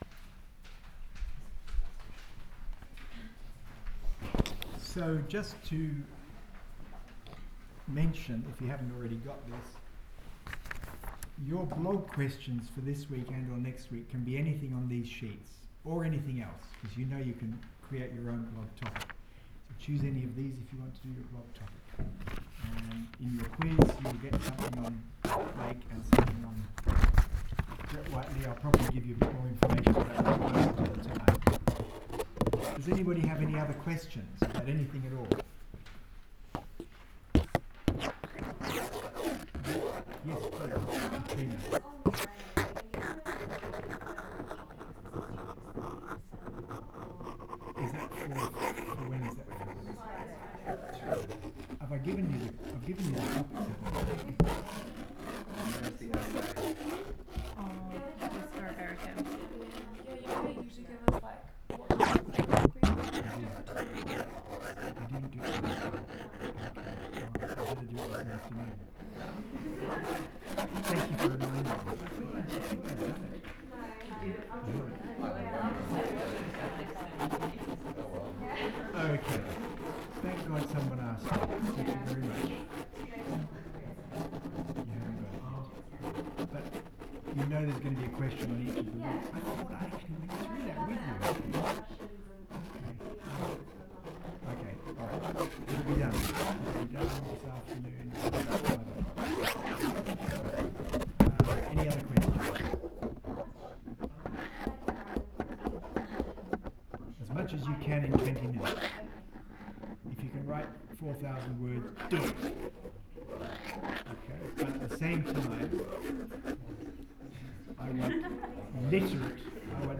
in the print room NSW Art Gallery